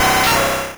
Cri d'Insécateur dans Pokémon Rouge et Bleu.